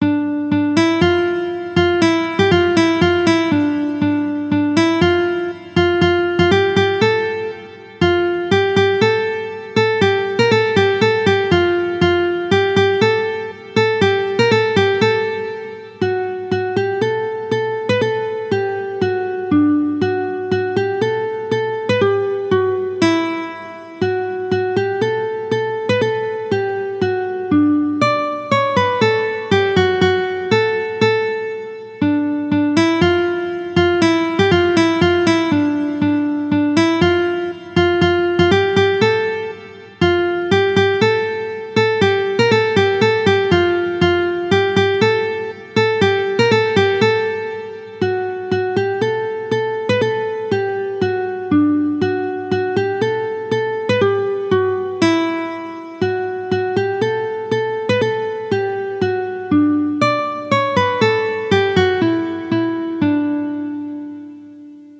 Les morceaux ont été adaptés pour être jouables aisément sur un accordéon diatonique Sol-Do (les diatos joueront la première voix sur la partition) .